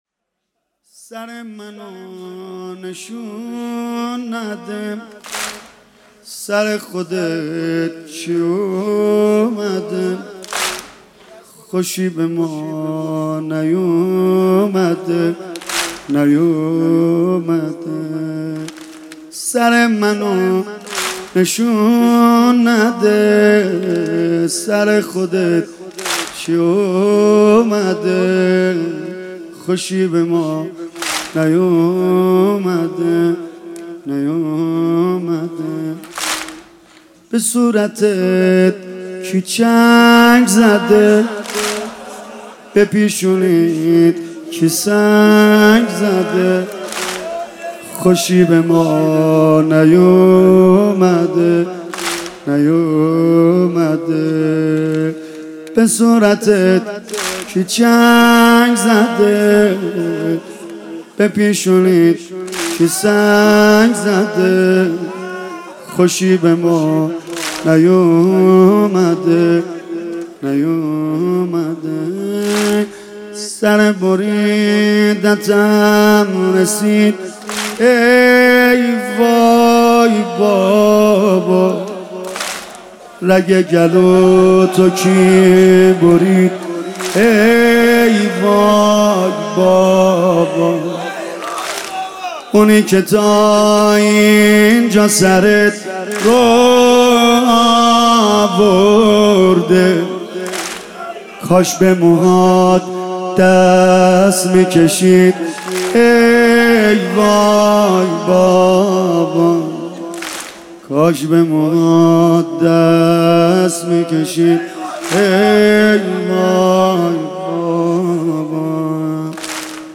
شب سوم محرم - به نام نامیِ حضرت رقیه(س)